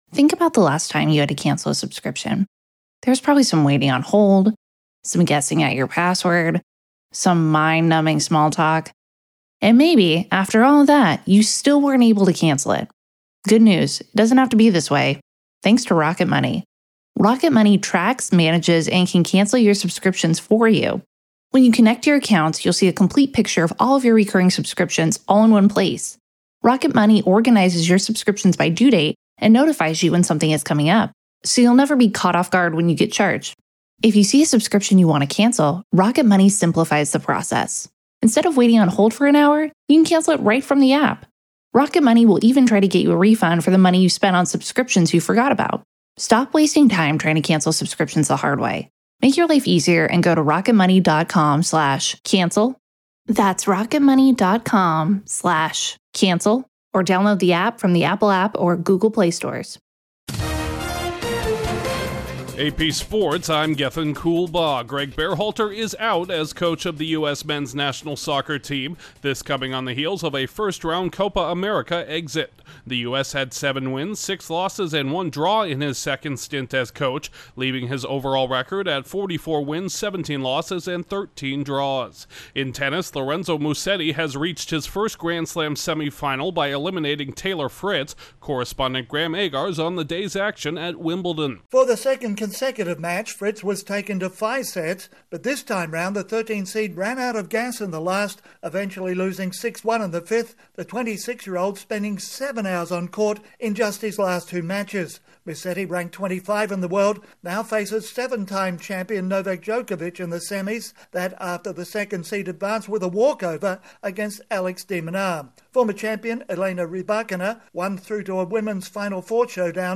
AP Sports SummaryBrief